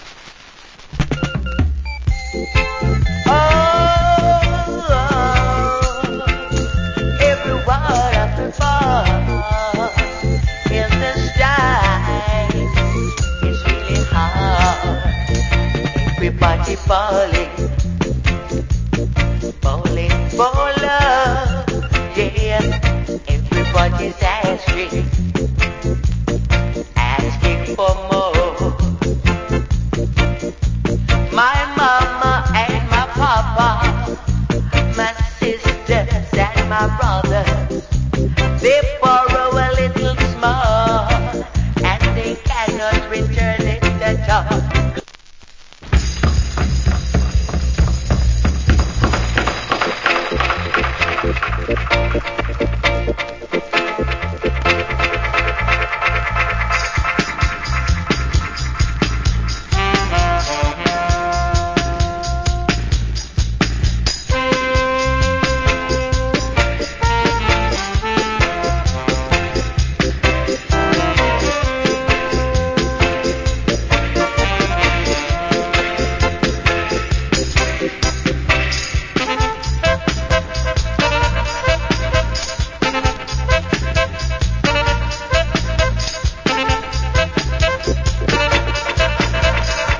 Good Reggae Vocal.